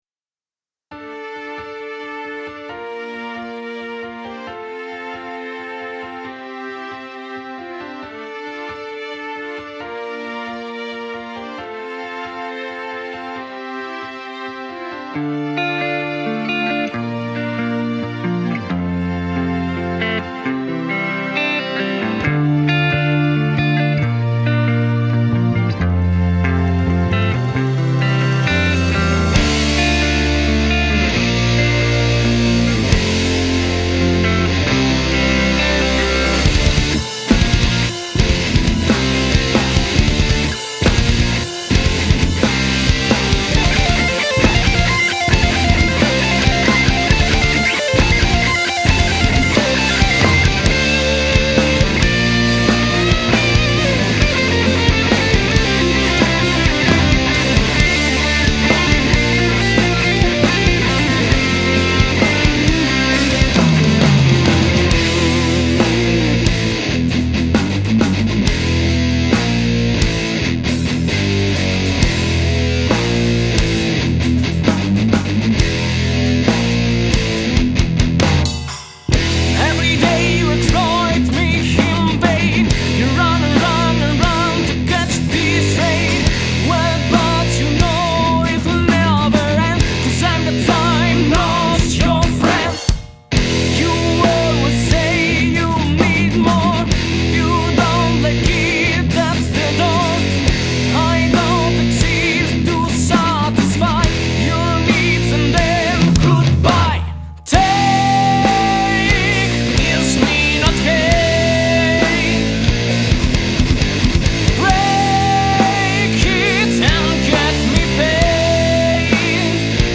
alternative metal band